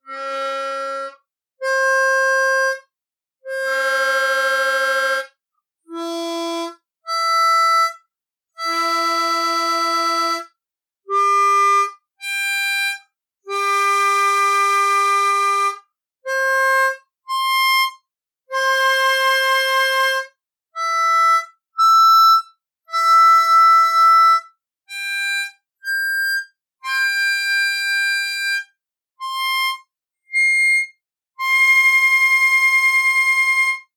We then tested the harmonica to see if it was in tune with itself by playing pairs of octave notes. You can hear from the result that it was badly off pitch and dissonant.
Eastar-Pink-Blow-Octaves.mp3